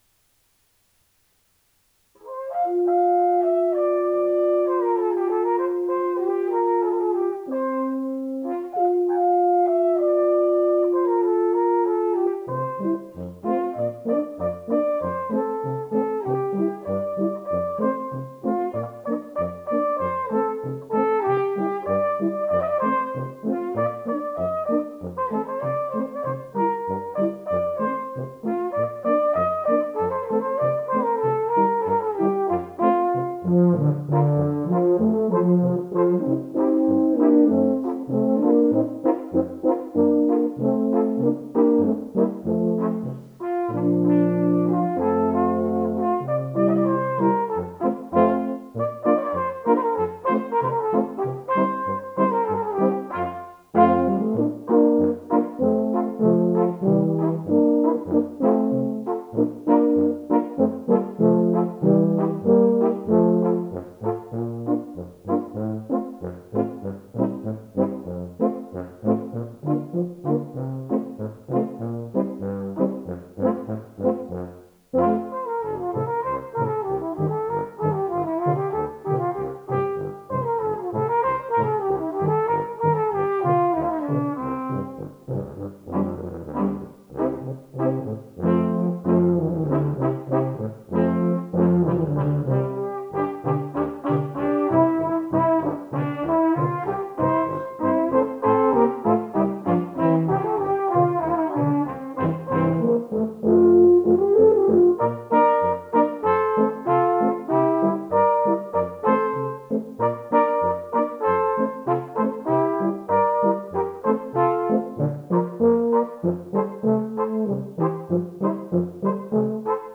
3.3_torontovka-smallbayan.flac (18.22 MB)